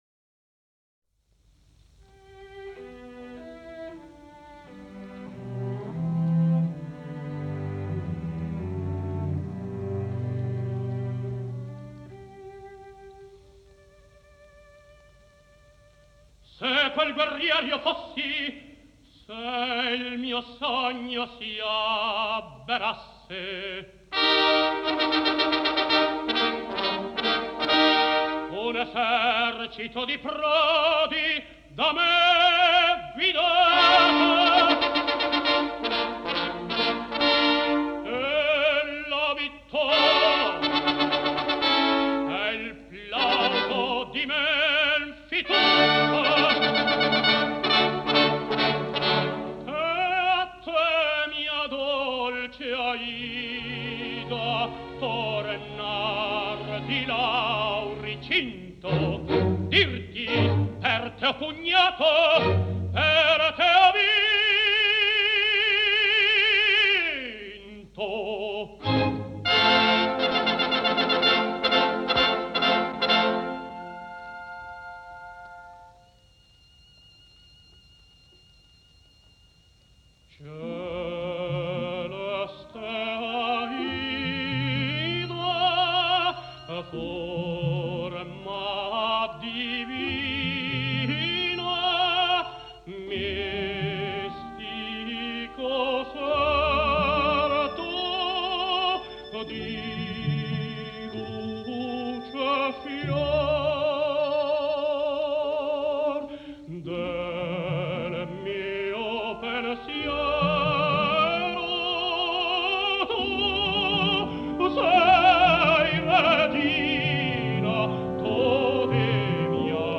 Жанр: Opera